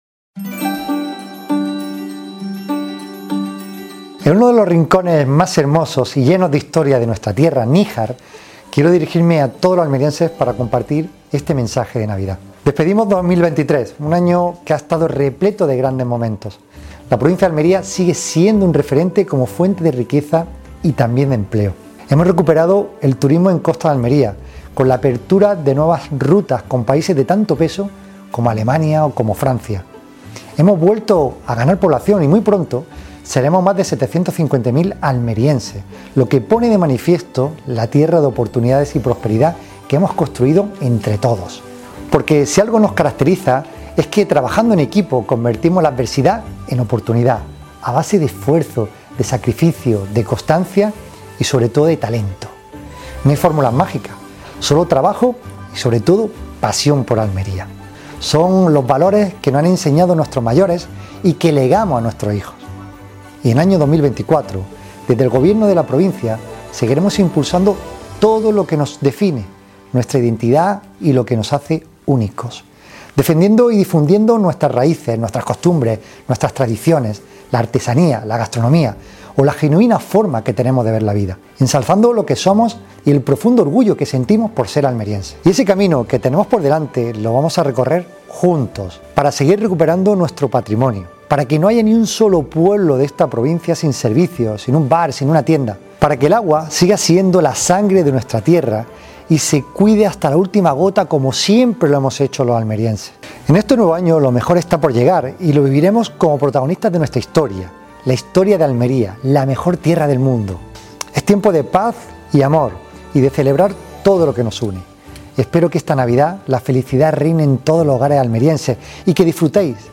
La alfarería Ángel y Loli, donde se mantiene viva la tradición artesana de varias generaciones del oficio de alfarero, tan ligado a la provincia de Almería, y El Portillo, un bello rincón del casco antiguo de Níjar decorado con motivos navideños y otros autóctonos como artesanía de cerámica y esparto, han sido los espacios desde los que el presidente comparte su mensaje de Navidad con toda la ciudadanía.
Javier-A.-Garcia-Mensaje-de-Navidad.mp3